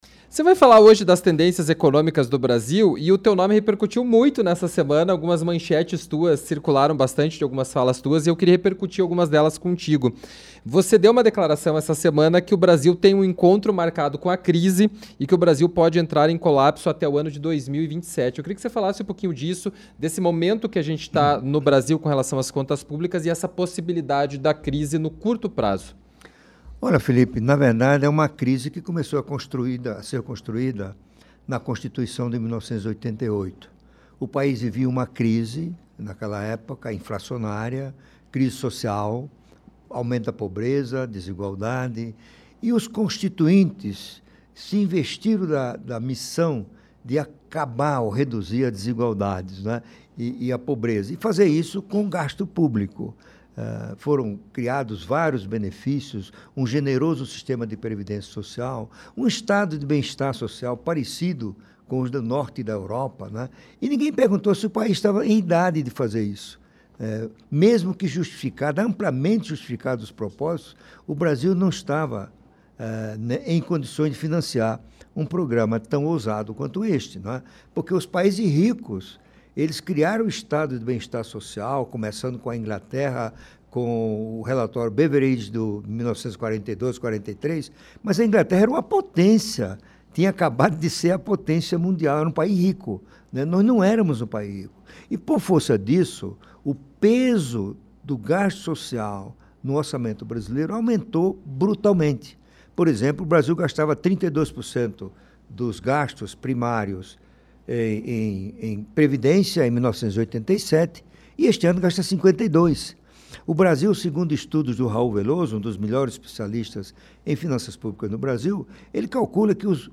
ENTREVISTA-MAILSON-DA-NOBREGA.mp3